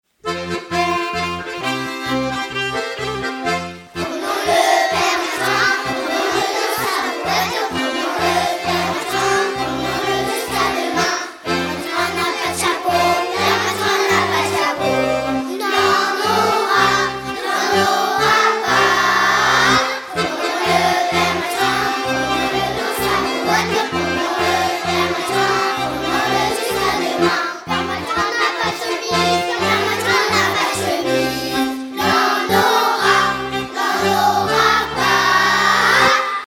carnaval, mardi-gras
Les enfants des Olonnes chantent
Pièce musicale éditée